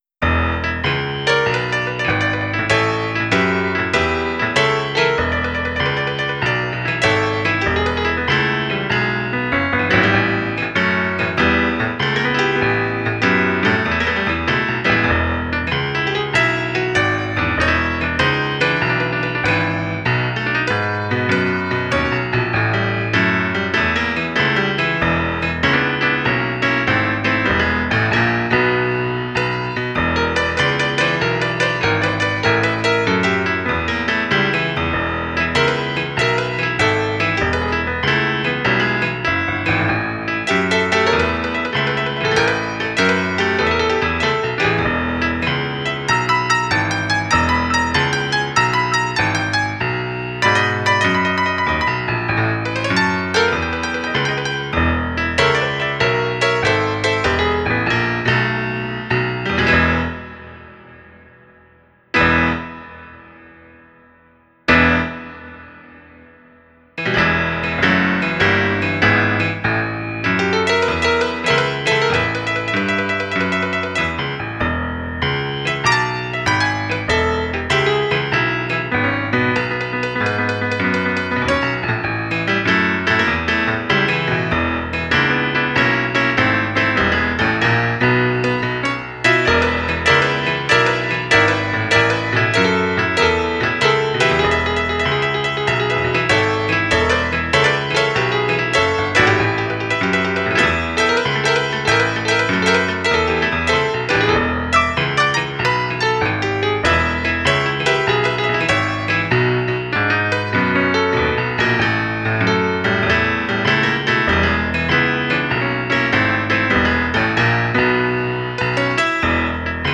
Piano tocando Swing
swing
piano
melodía